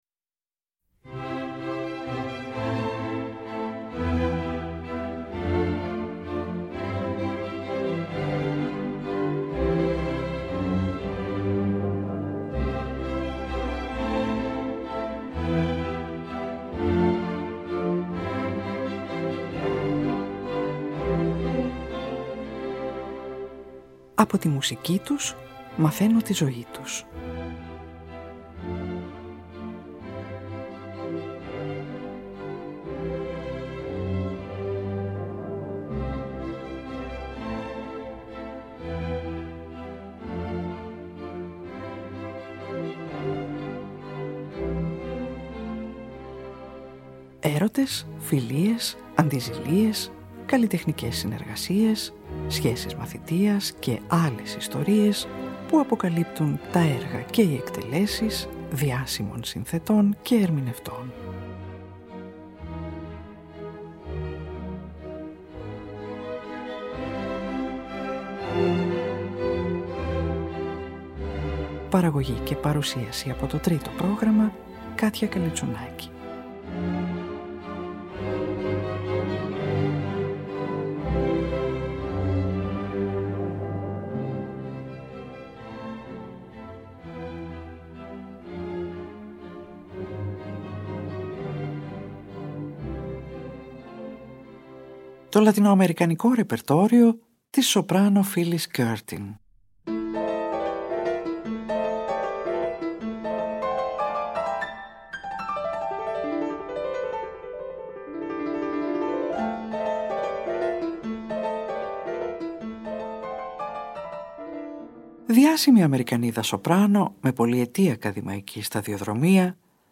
σοπράνο